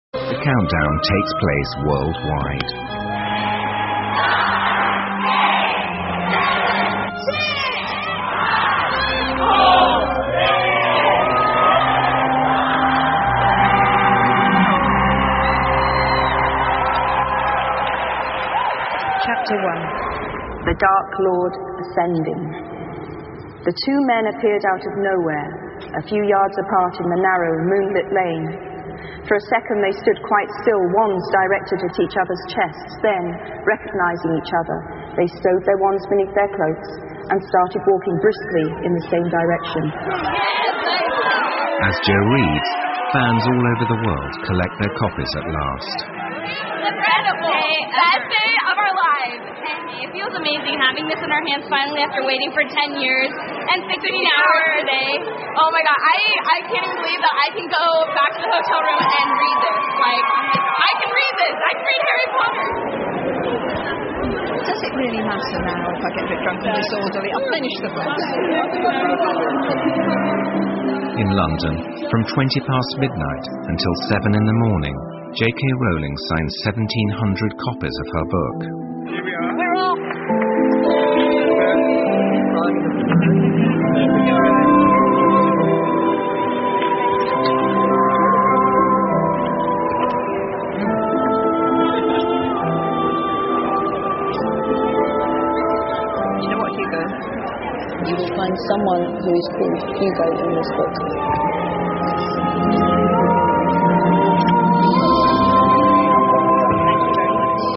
生命中的一年 第14期：罗琳首发现场朗读第一章 听力文件下载—在线英语听力室